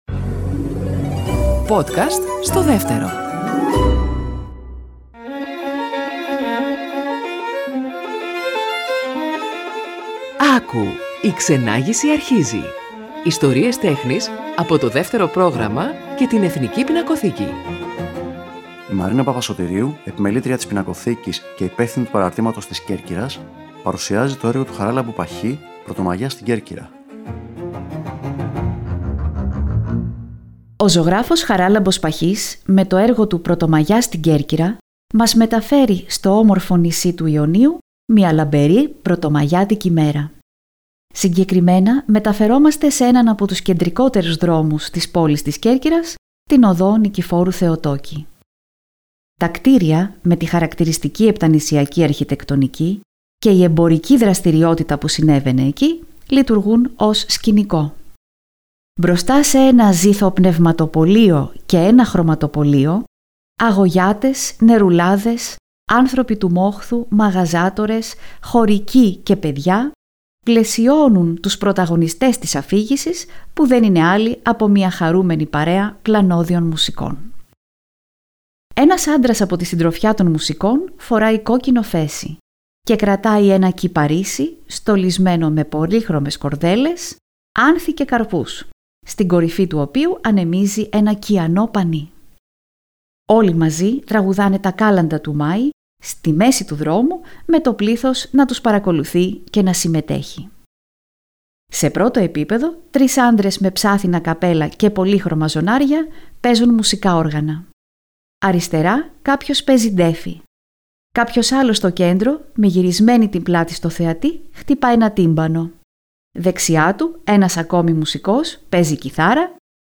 Κείμενο / Αφήγηση